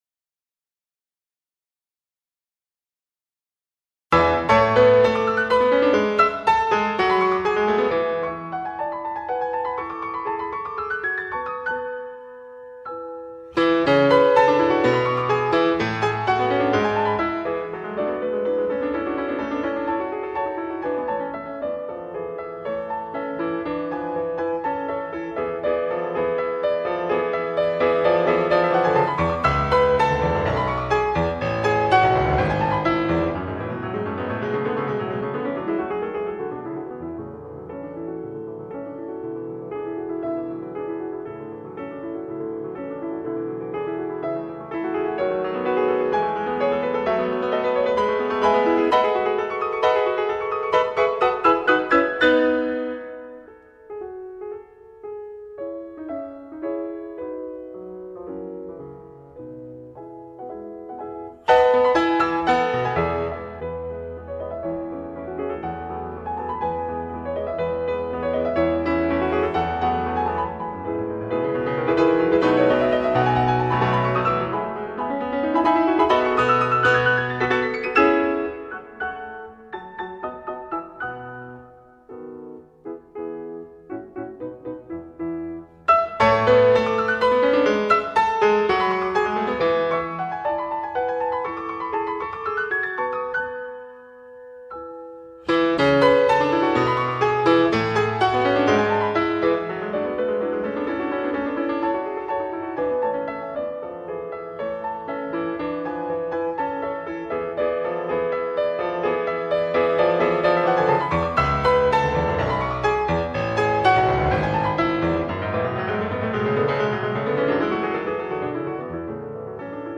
Sa technique est absolue et c’est dans l’objectivité de son jeu que la partition peut s’intérioriser.
Et un extrait sonore, le dernier mouvement de l’opus 101 de Beethoven, dans une interprétation de référence.
pollini-op-101.mp3